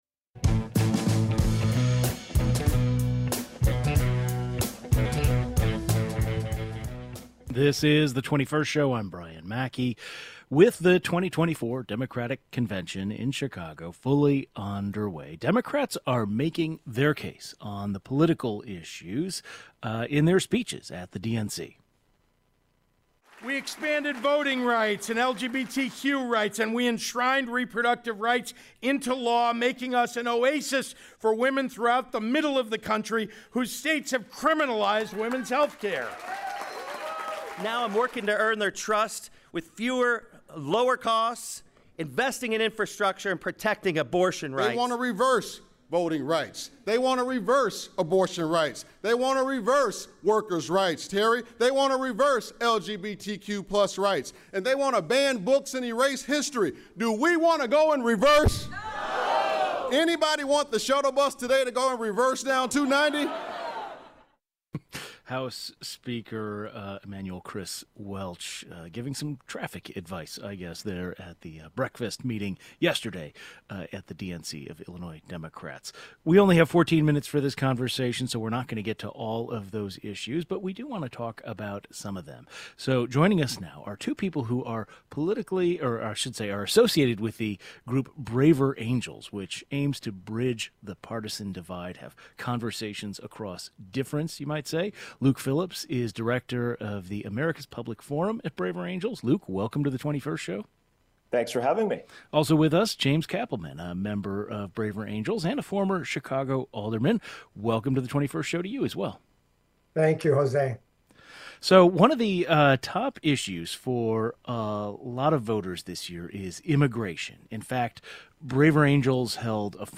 Two members of Braver Angels, an organization aimed at bridging the partisan gap in our country by bringing people from all sides of the political spectrum together join the 21st today.